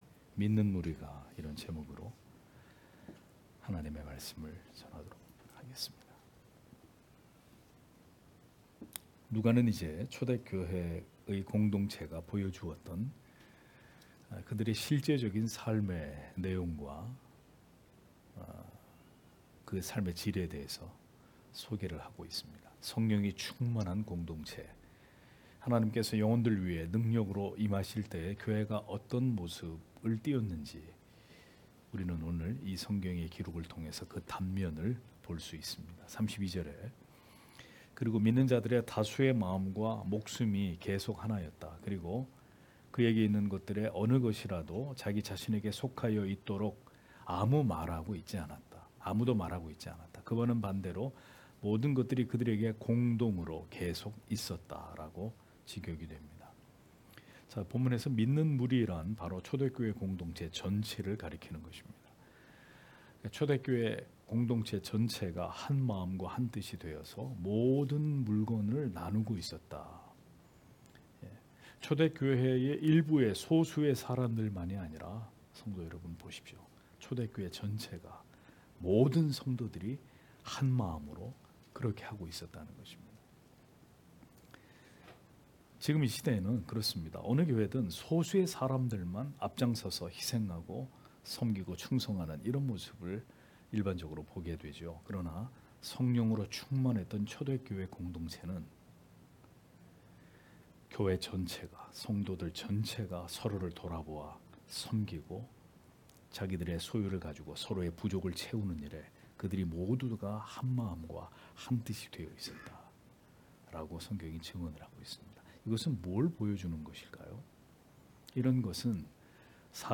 금요기도회 - [사도행전 강해 29]믿는 무리가(행 4장 32-37절)